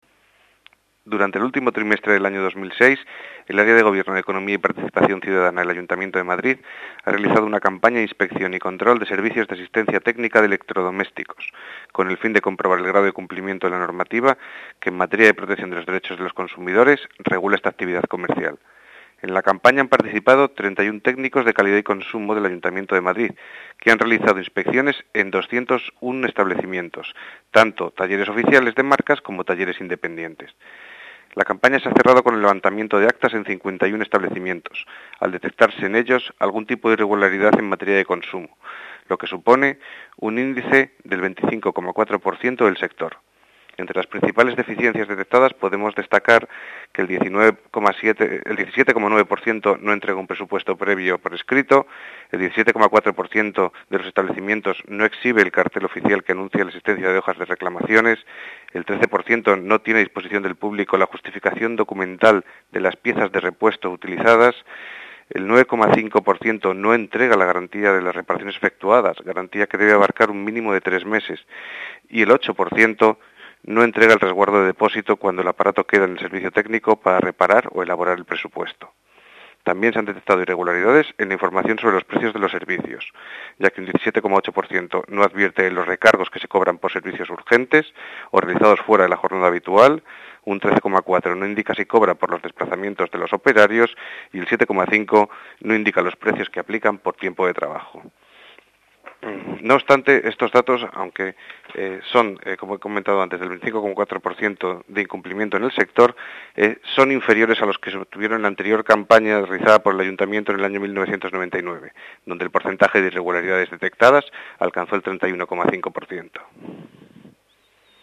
Nueva ventana:Declaraciones de Daniel López, director general de Comercio y Consumo